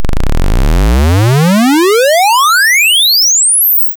oscillator-square-expected.wav